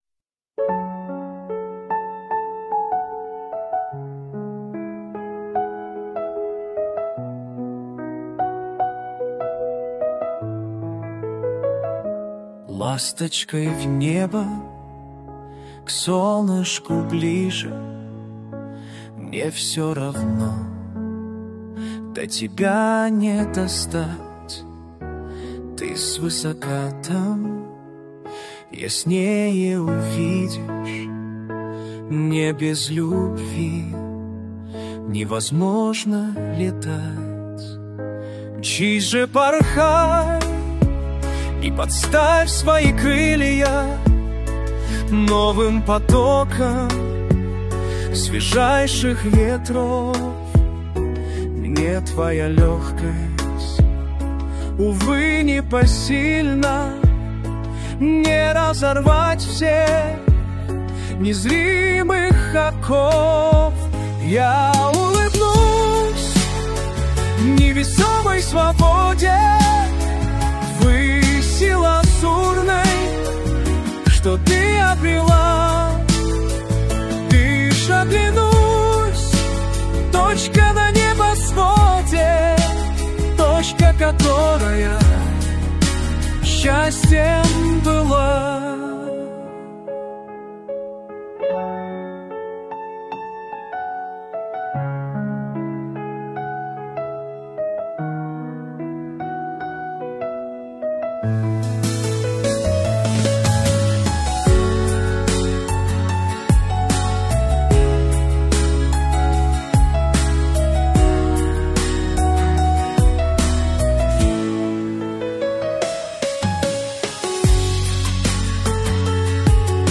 Слова - автора, виконання пісні - штучне
ТИП: Пісня
СТИЛЬОВІ ЖАНРИ: Ліричний
ВИД ТВОРУ: Авторська пісня